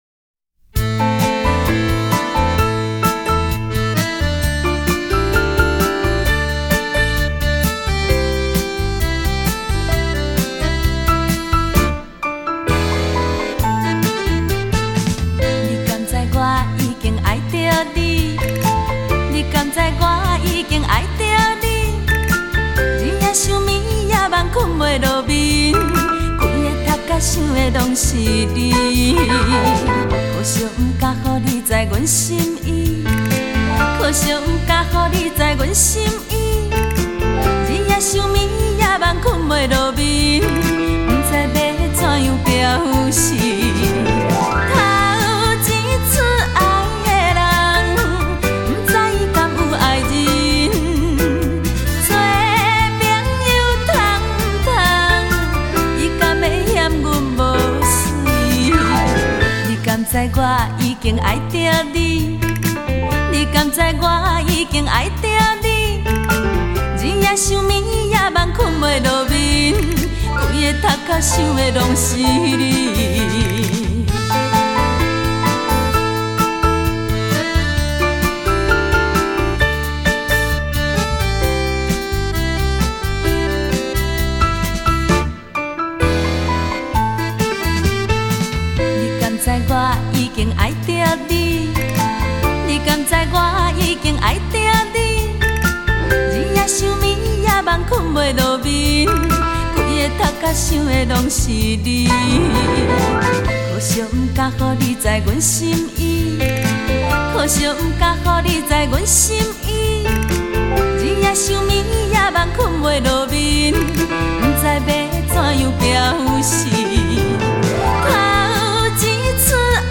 柔情心滋味 美声新感受